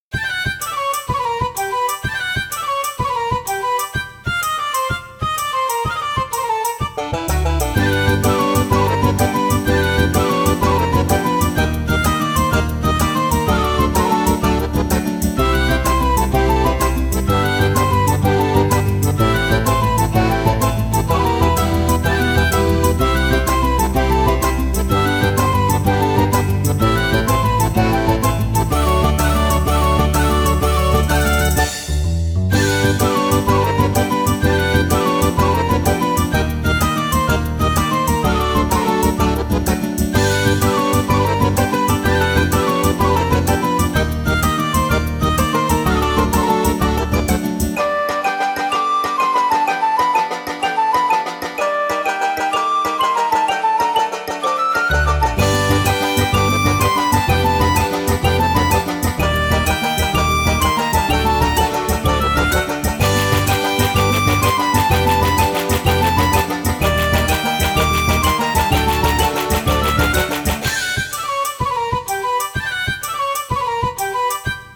ogg(L) 村 陽気 北欧風 リズミカル
笛と弦の軽やかな旋律が北欧音楽の香りを漂わせる。